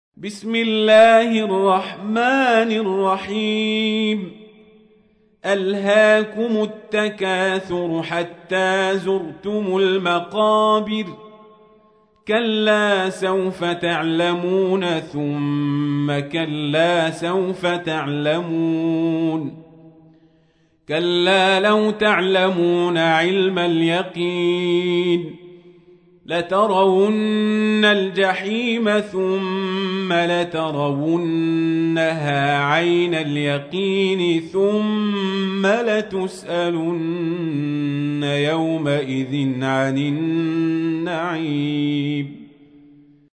تحميل : 102. سورة التكاثر / القارئ القزابري / القرآن الكريم / موقع يا حسين